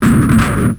Index of /90_sSampleCDs/Zero-G - Total Drum Bass/Instruments - 3/track70 (Hits and Bits)